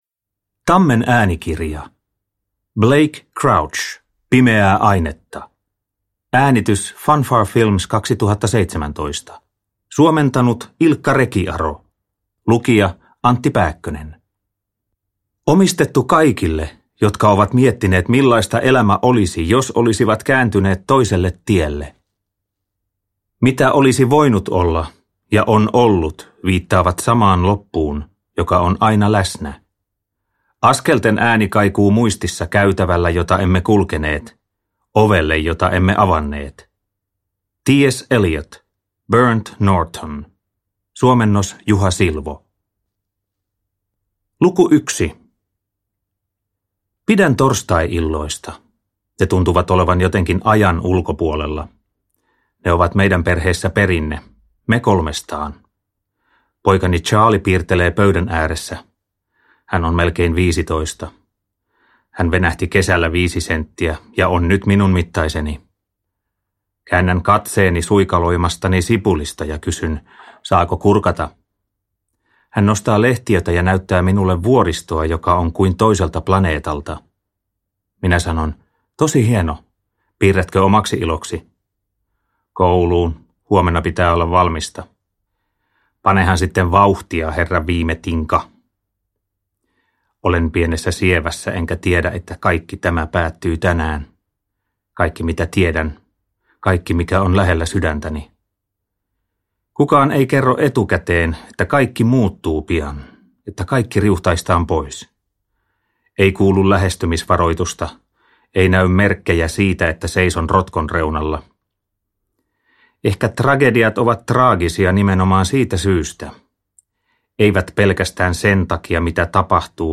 Uppläsare: Antti Pääkkönen